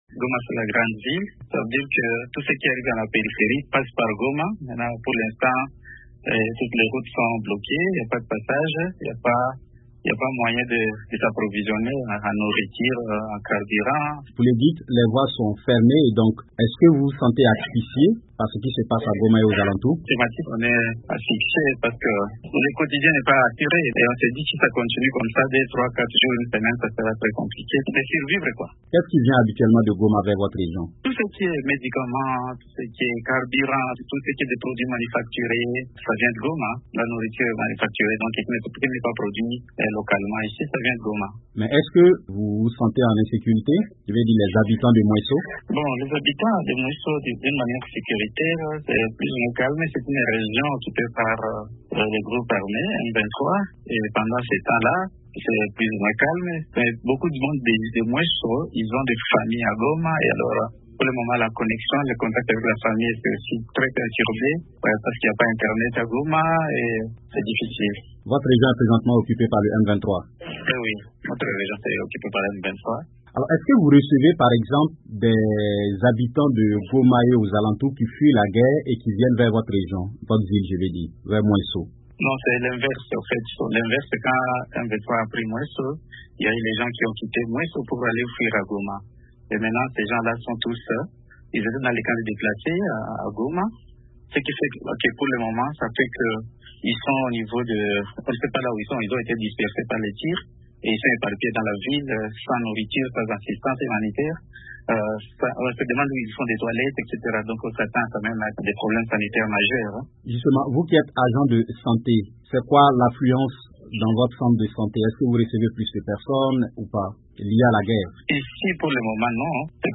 Témoignage d’un agent de santé sur la situation locale à Mwesho, à 100 km de Goma